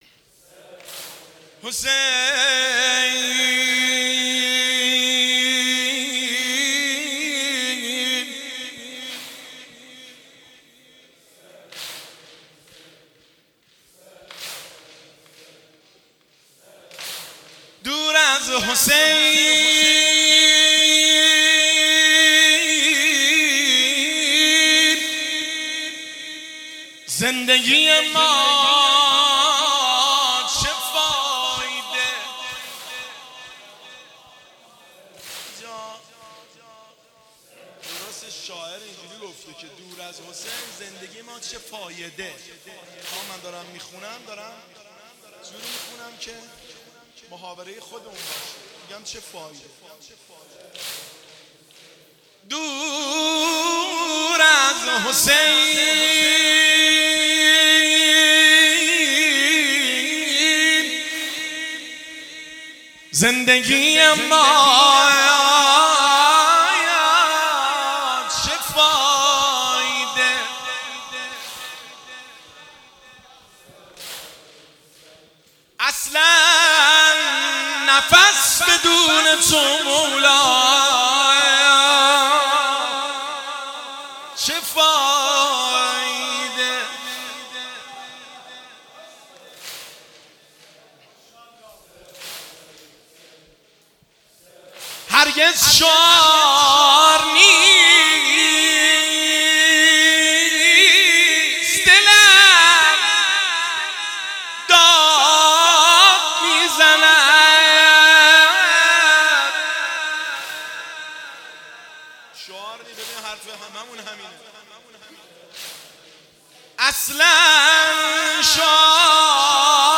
هیئت بین الحرمین طهران
دور-از-حسین-شعرخوانی.mp3